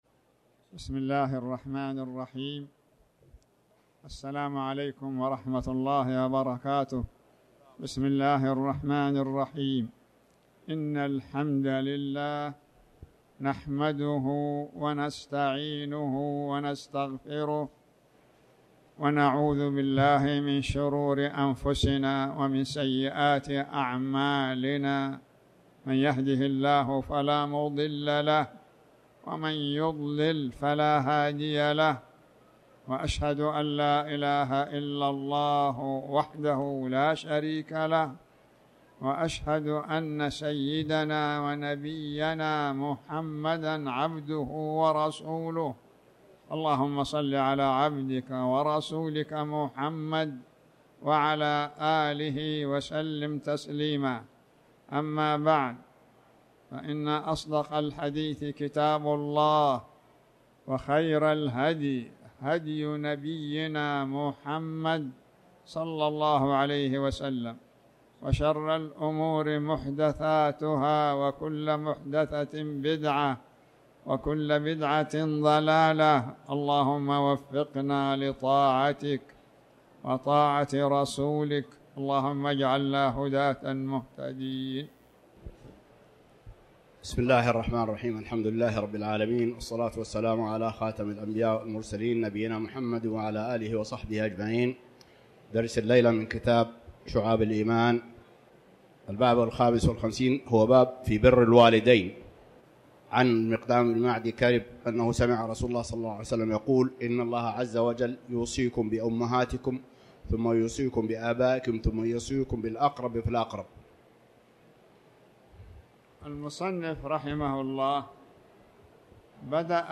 تاريخ النشر ١٧ شوال ١٤٣٩ هـ المكان: المسجد الحرام الشيخ